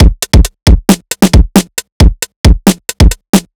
Bounce Linn Break 1 135.wav